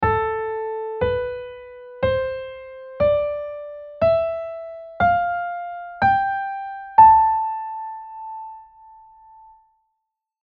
Start on A, the sixth scale degree of the C Ionian, play your way up as always, and end on A. What do you hear?
A Aeolian mode
That’s right – we’ve arrived at the natural minor scale.
A-Aeolian.mp3